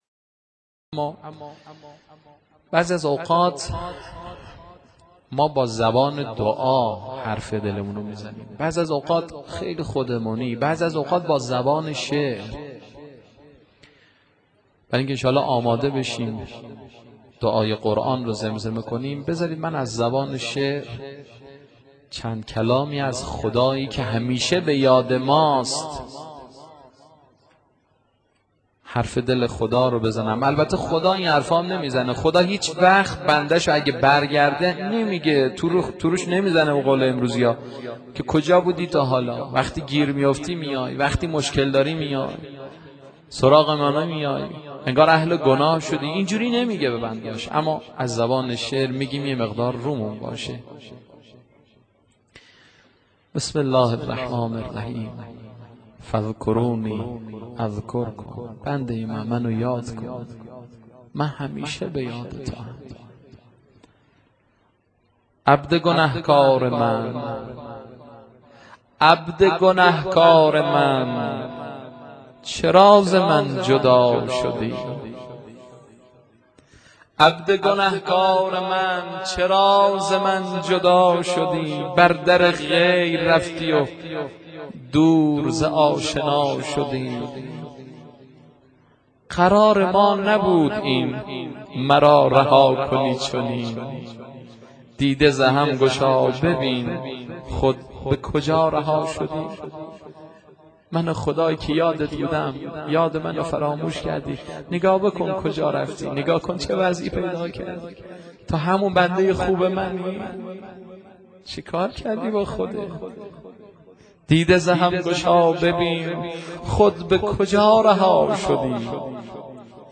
مناجات و دعای قرآن